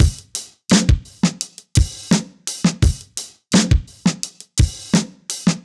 Index of /musicradar/sampled-funk-soul-samples/85bpm/Beats
SSF_DrumsProc1_85-03.wav